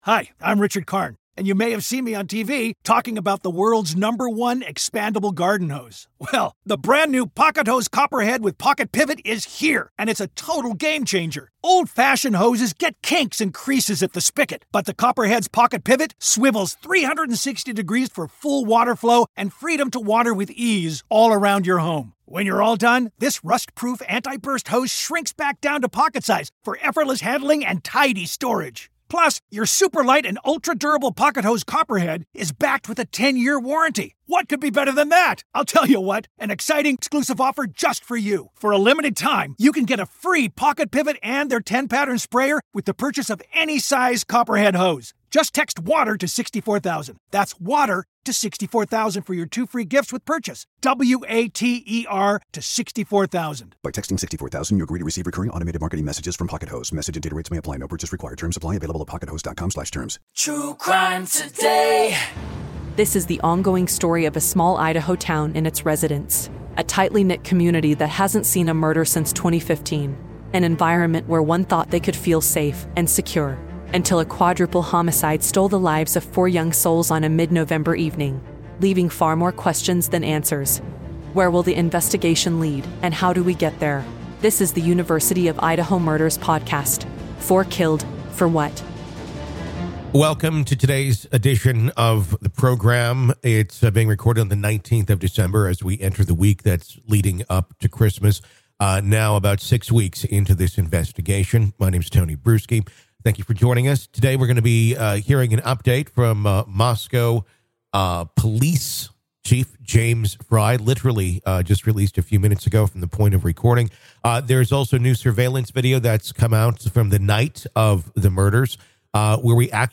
New Footage & Communications Interview | 4 Killed For What?